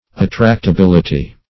Search Result for " attractability" : The Collaborative International Dictionary of English v.0.48: Attractability \At*tract`a*bil"i*ty\, n. The quality or fact of being attractable.
attractability.mp3